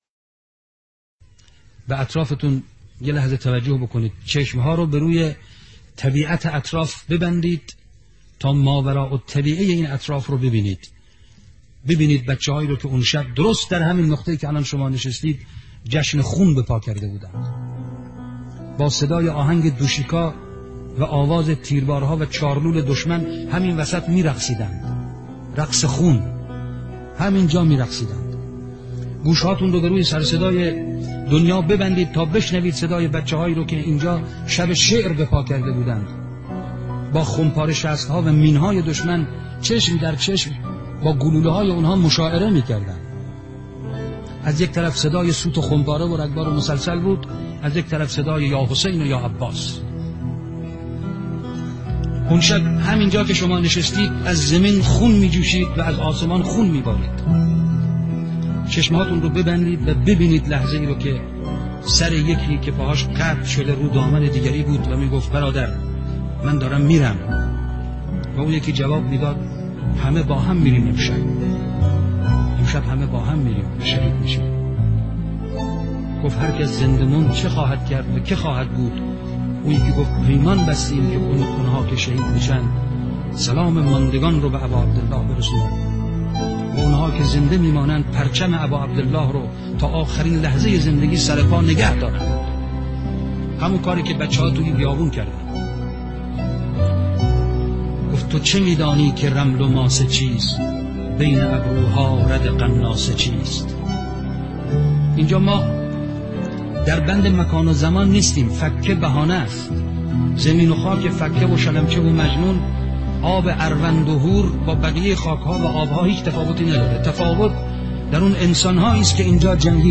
روایتگری